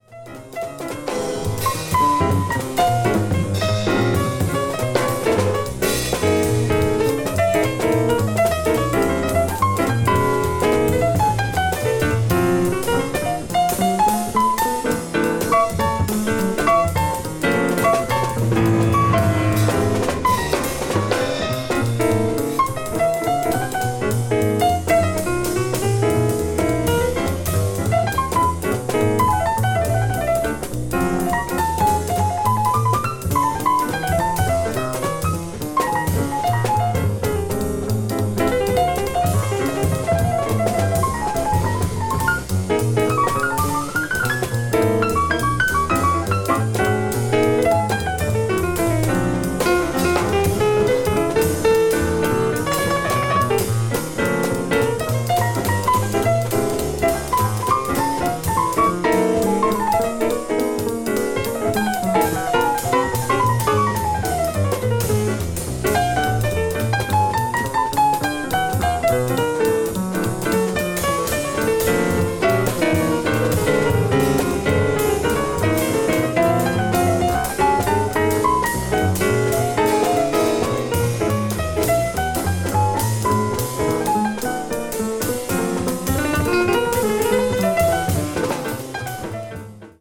media : EX/EX(some slightly noises.)
a piano trio